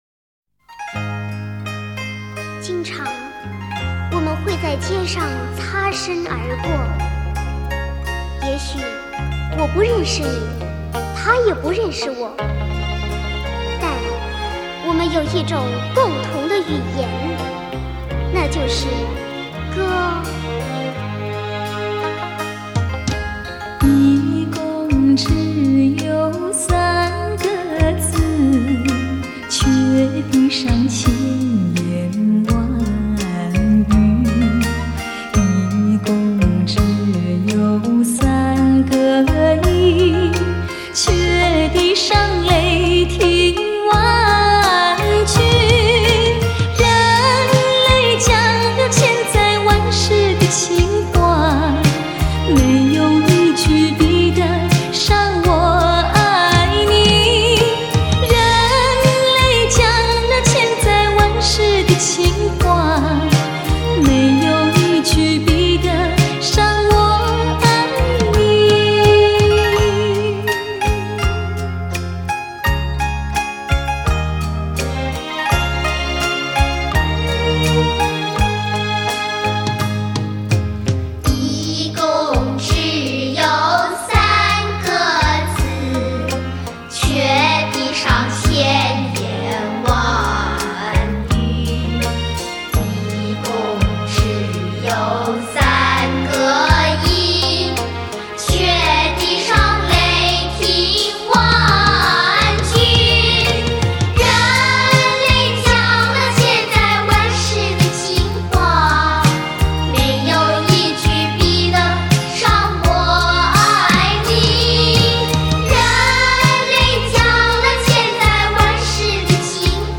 恰恰旋律